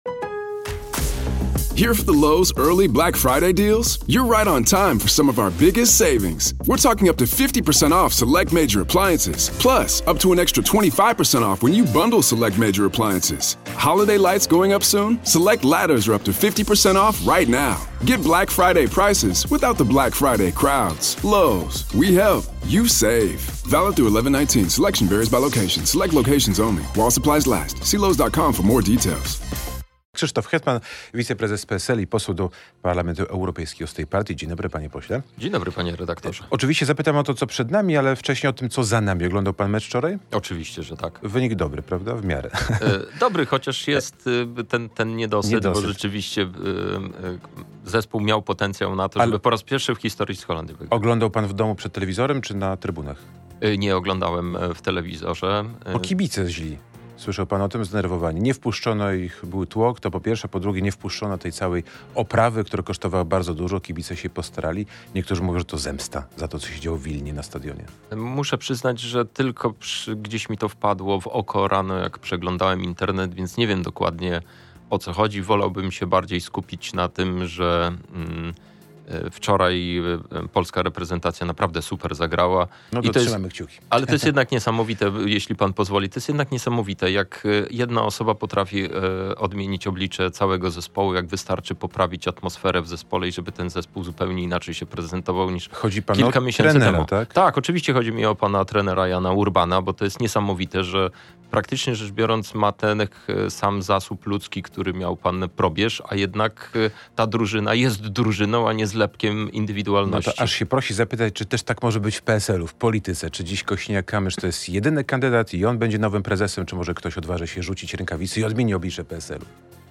W sobotnie poranki, tuż po godz. 8:30 Krzysztof Ziemiec zaprasza na rozmowy nie tylko o polityce.